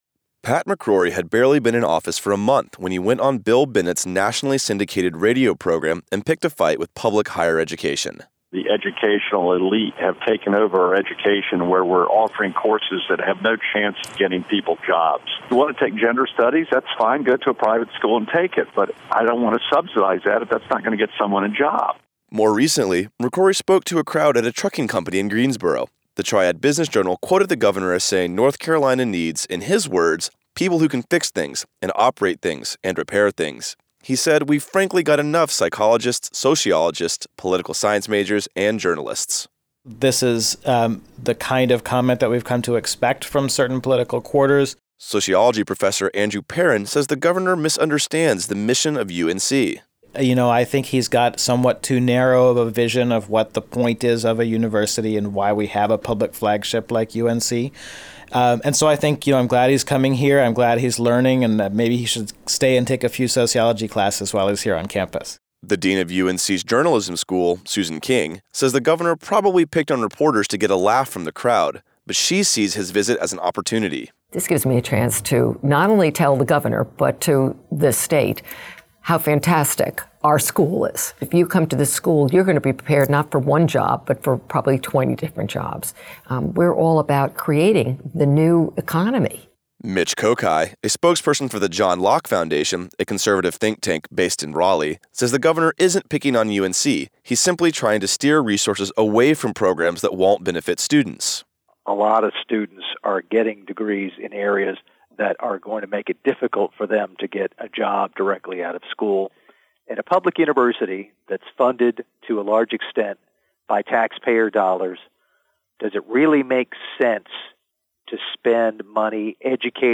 Gov. McCrory speaks at University Day